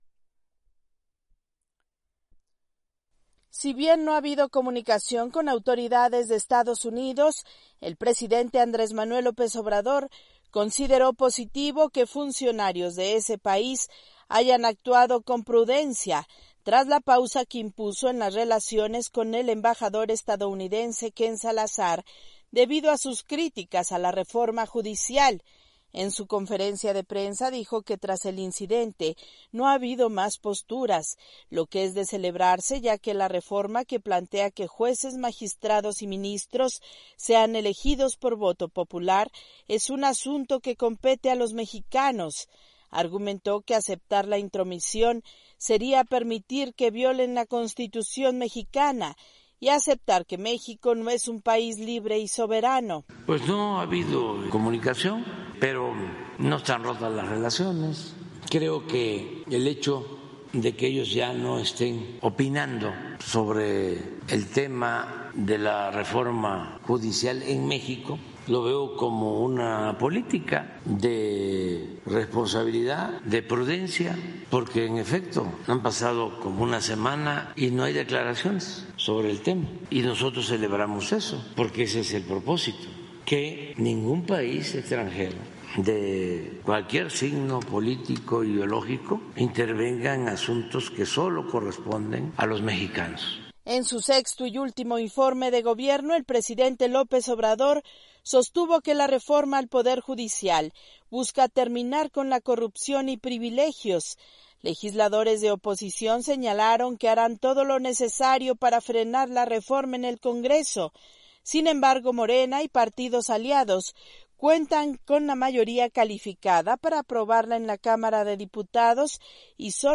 El presidente de México, Andrés Manuel López Obrador, celebró que Estados Unidos ya no emitió críticas a la iniciativa de reforma judicial. Desde México informa la corresponsal de la Voz de América